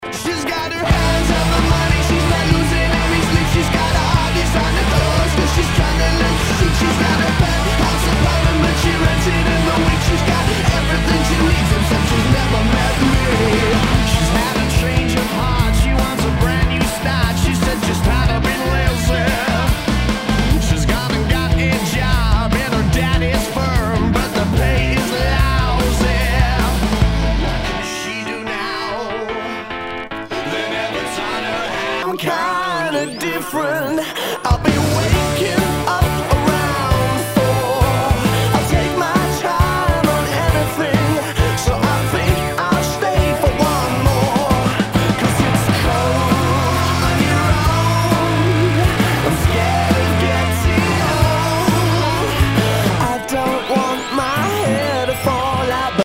ROCK/POPS/INDIE
ナイス！インディーロック！